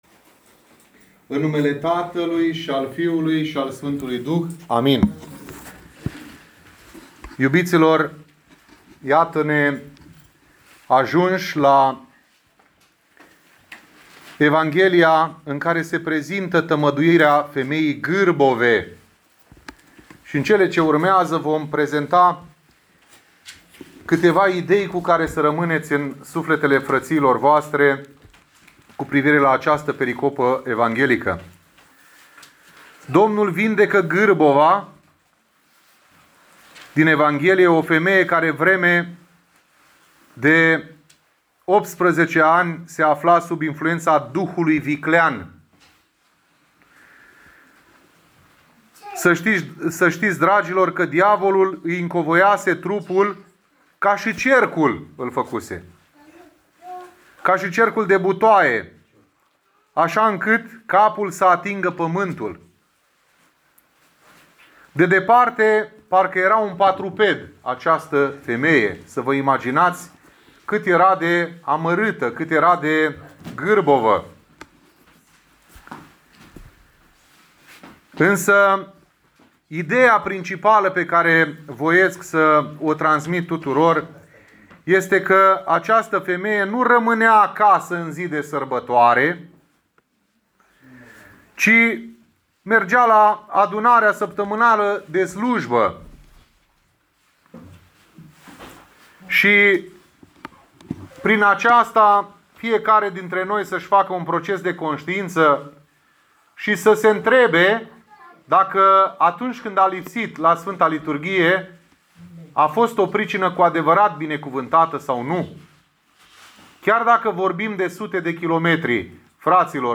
Predica poate fi descărcată în format audio mp3 de aici: